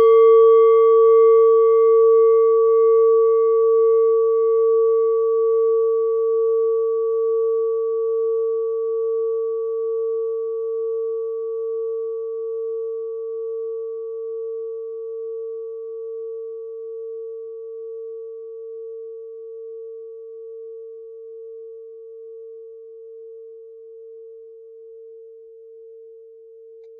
Nepal Klangschale Nr.4
Hörprobe der Klangschale
Was den Klang anbelangt, sind handgearbeitete Klangschalen immer Einzelstücke.
(Ermittelt mit dem Filzklöppel)
Klangschale-Durchmesser: 12,9cm
klangschale-nepal-4.wav